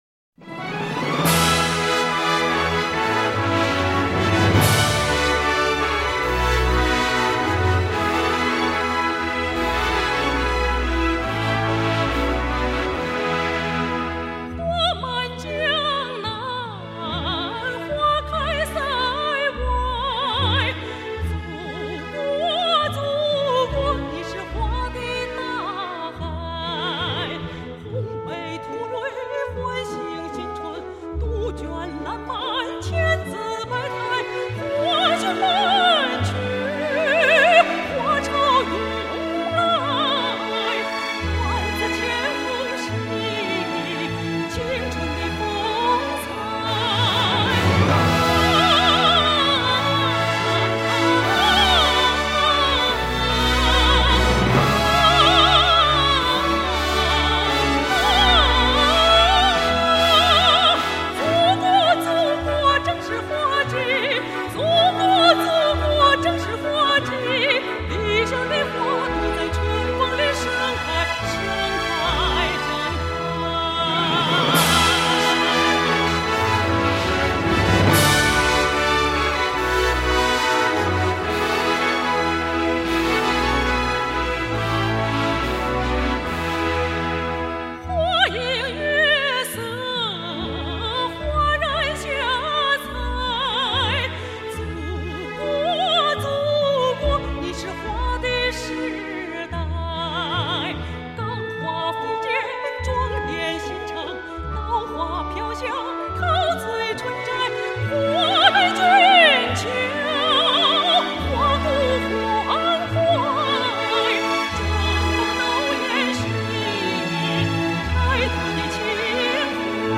著名女高音歌唱家，中国广播艺术团一级演员，中央